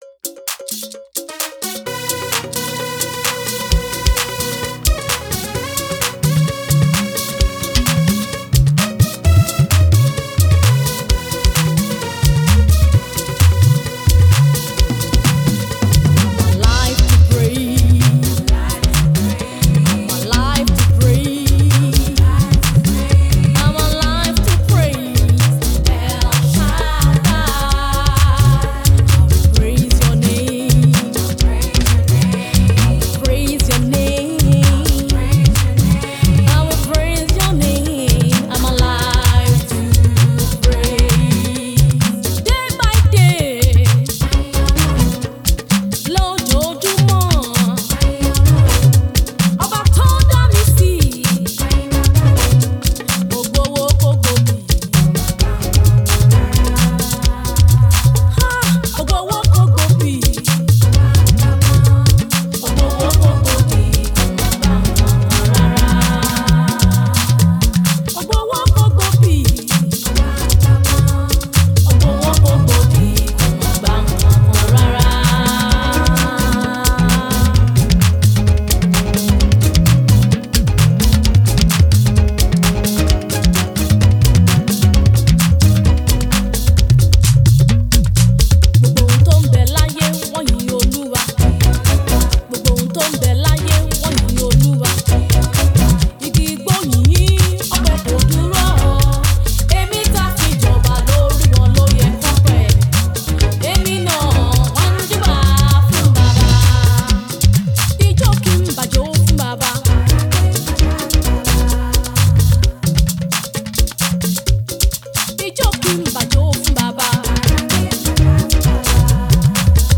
a new song of praise
a medley of 9 songs with a mixture of 3 major languages